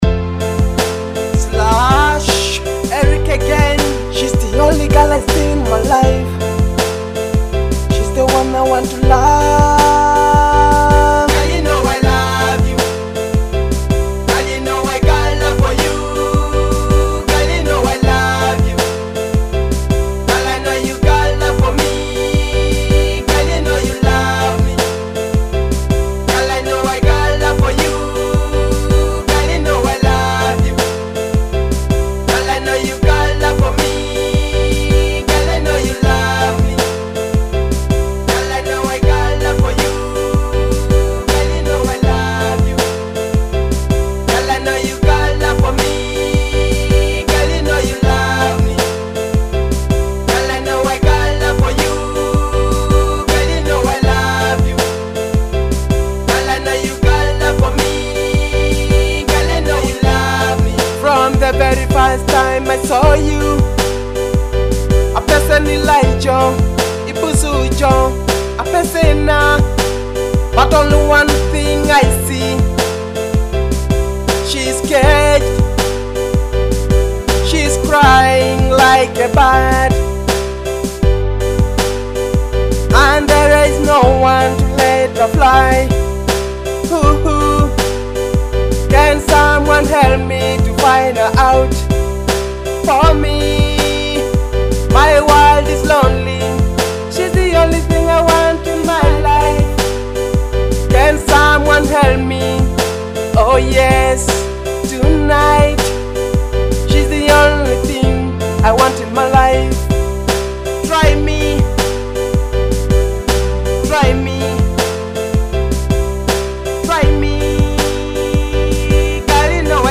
Teso music